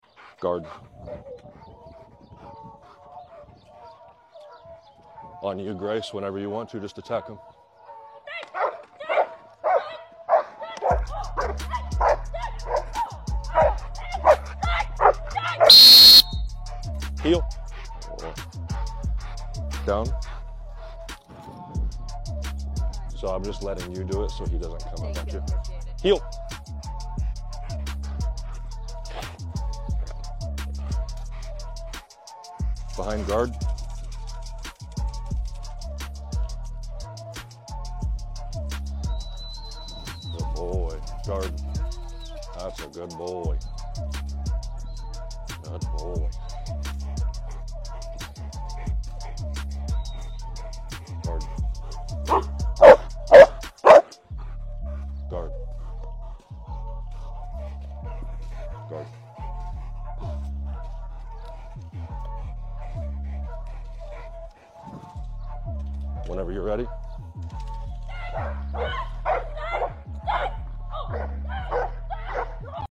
Getting rid of muzzle punching and barking in the “Guard”. He loves to muzzle fight and tries to fight in seated “Guard”.